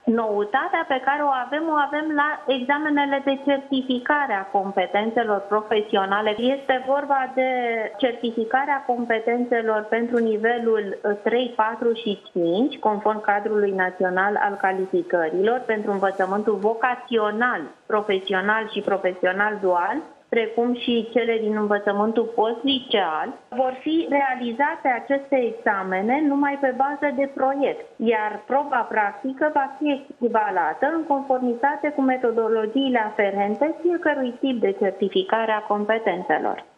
Examenul de Evaluare Naționalã va începe pe 15 iunie, iar cel de Bacalaureat pe 22 iunie – a mai anunțat asearã la un post de televiziune Monica Anisie.